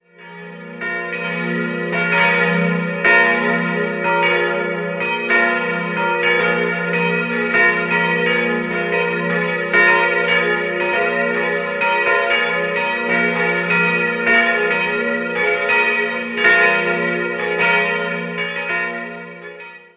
Diese wurde im Jahr 1897 erbaut. 3-stimmiges TeDeum-Geläute: f'-as'-b' Die Glocken 1 und 3 stammen aus der Gießerei Junker in Brilon aus dem Jahr 1957, die mittlere Glocke wurde von Schilling (Apolda) im Jahr 1910 gegossen.